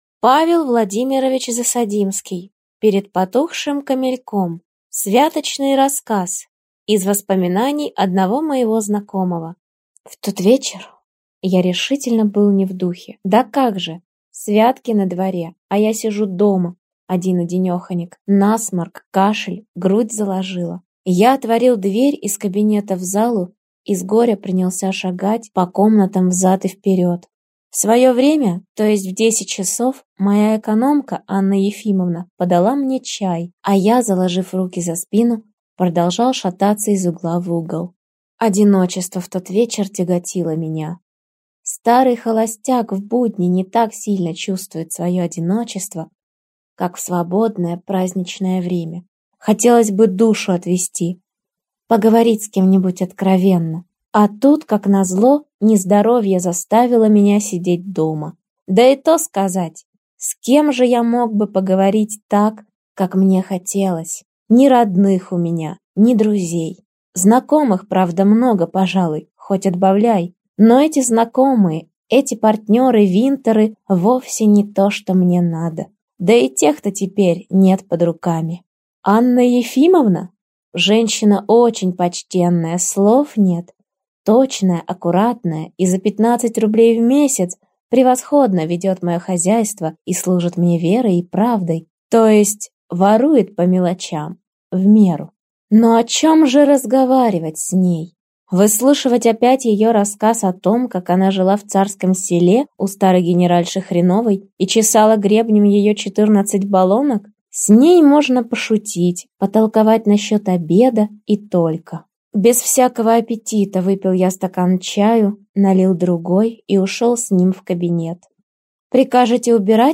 Аудиокнига Перед потухшим камельком | Библиотека аудиокниг